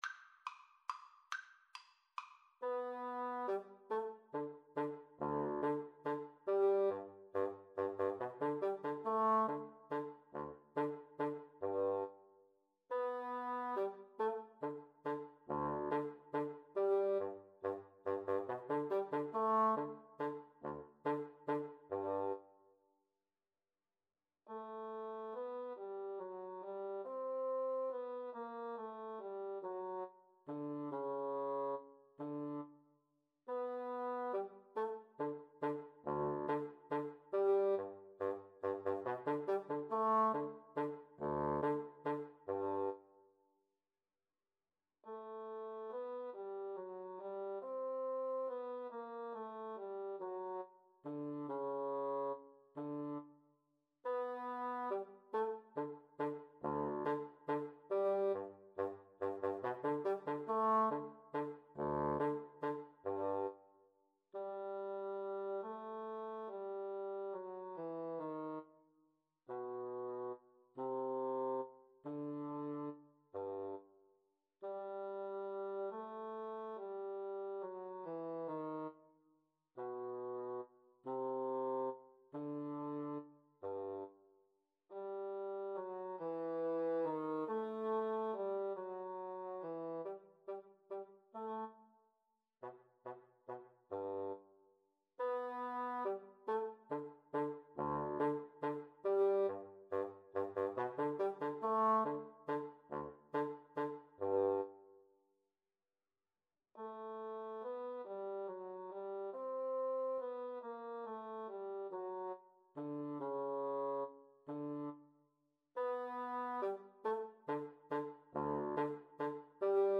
G major (Sounding Pitch) (View more G major Music for Bassoon Duet )
Allegretto - Menuetto =140
Bassoon Duet  (View more Easy Bassoon Duet Music)
Classical (View more Classical Bassoon Duet Music)